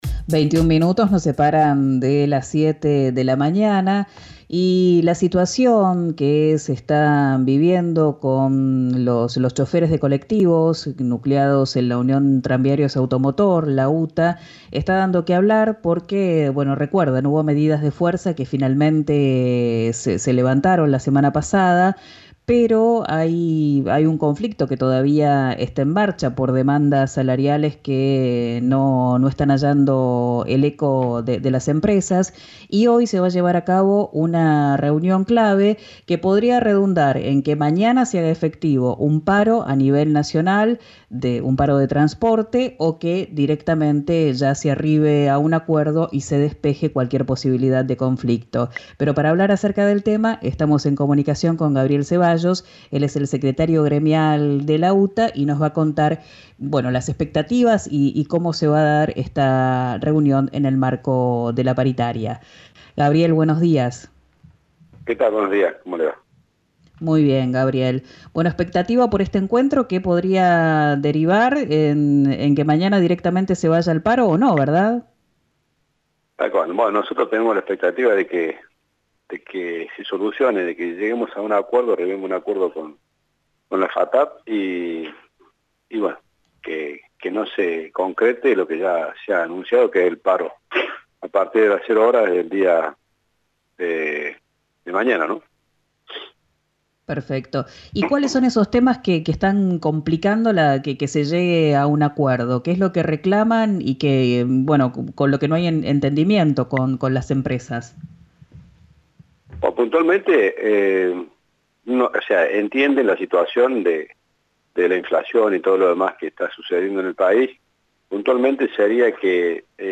habló con RÍO NEGRO RADIO y explicó que hubo una reunión en la mañana con autoridades de la Federación Argentina de Transportadores por Automotor de Pasajeros